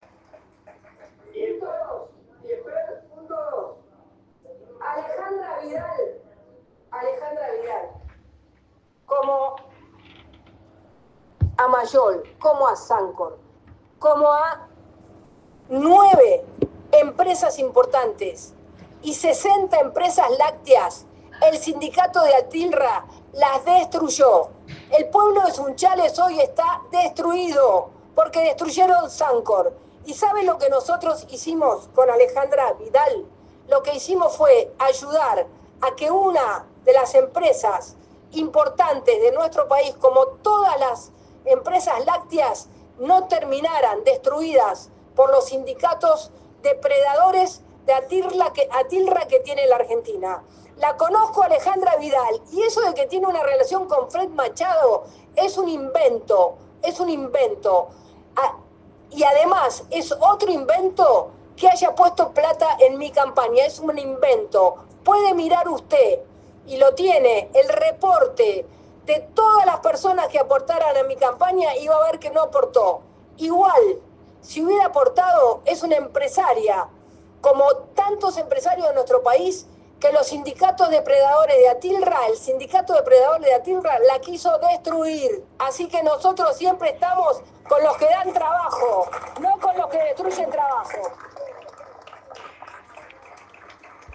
La ministra de seguridad del gobierno Argentino, Patricia Bullrich, se presentó este martes ante la Comisión de Presupuesto y Hacienda de la Cámara de Diputados para exponer sobre el Presupuesto 2026 correspondiente a su cartera.